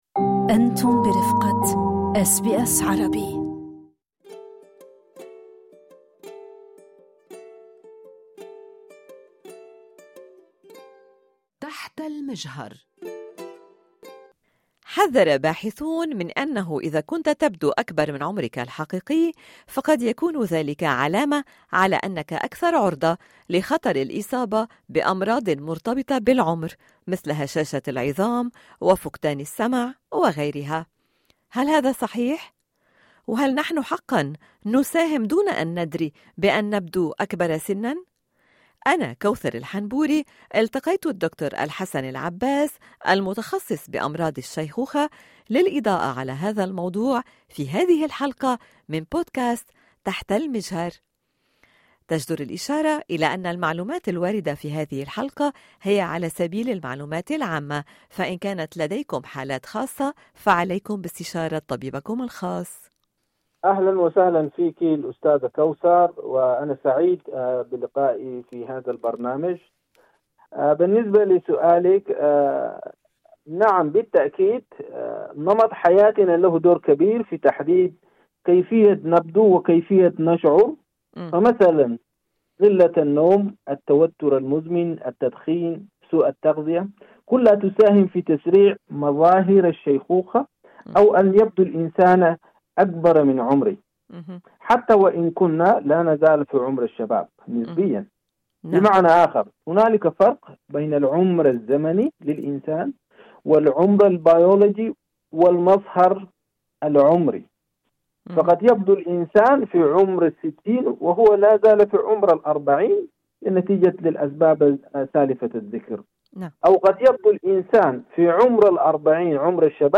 اشارة الى أن هذا اللقاء يقدم معلومات عامة فقط لمزيد من التفاصيل عن حالات خاصة عليكم باستشارة طبيبكم الخاص